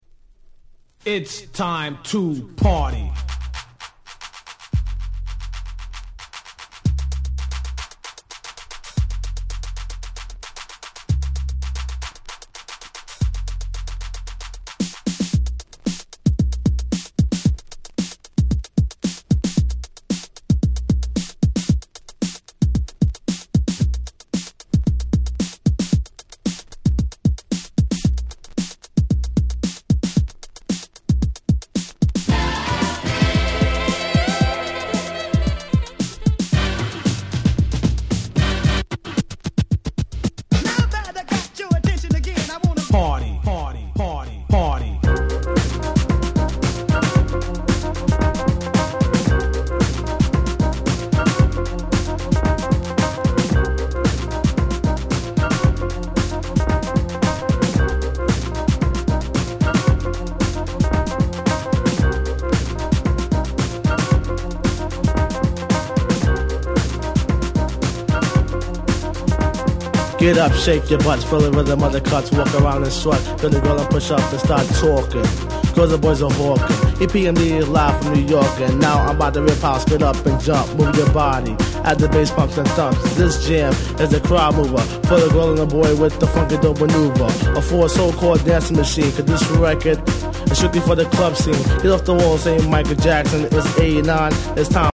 ※試聴ファイルは別の盤から録音してあります。
89' Smash Hit Hip Hop !!